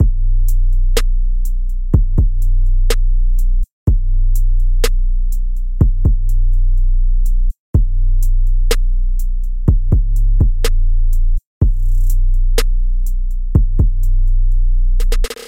细菌大鼓
描述：细菌桶